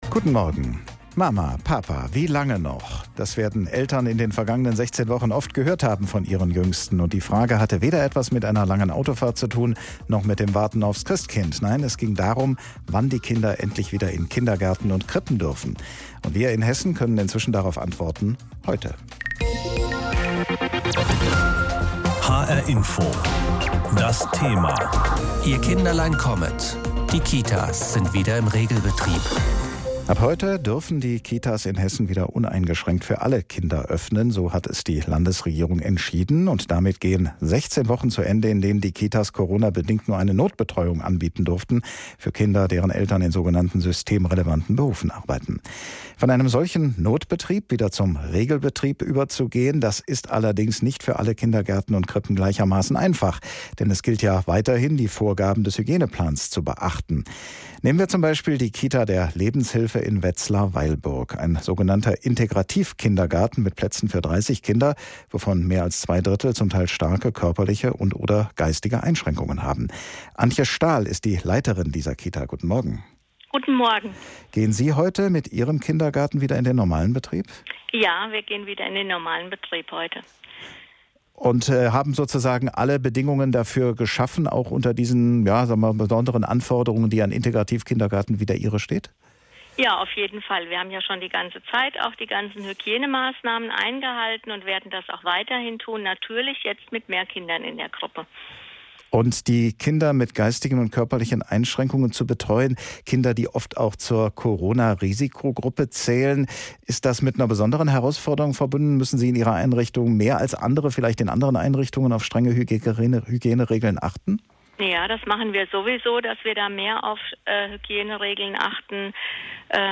hr-info-Interview